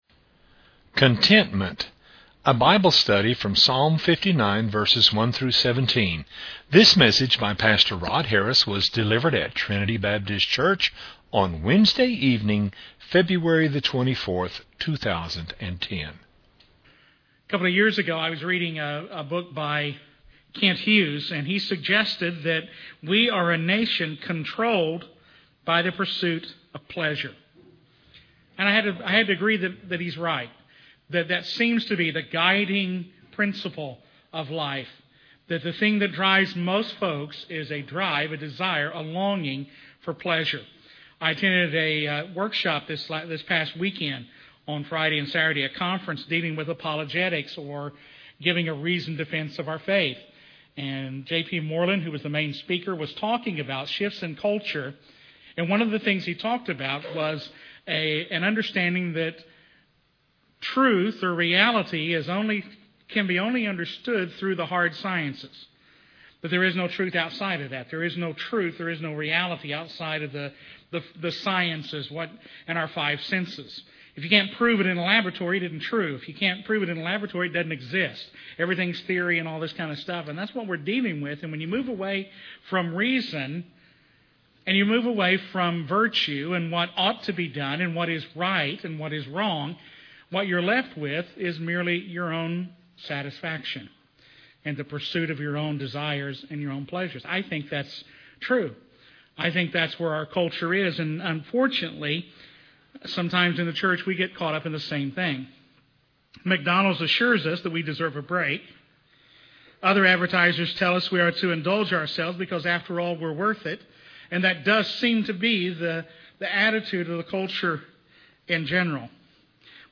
was delivered at Trinity Baptist Church on Wednesday evening, February 24, 2010.